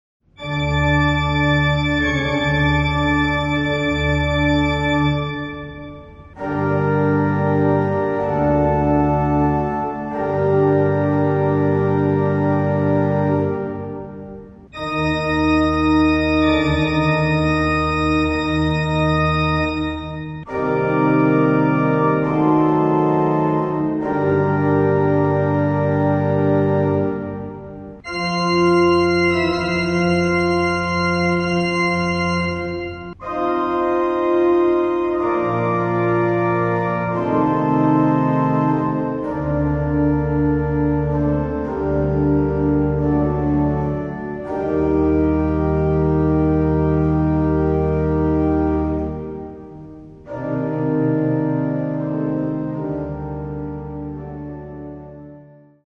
Organo "Amedeo Ramasco", Chiesa Parrocchiale di Crocemosso
Organo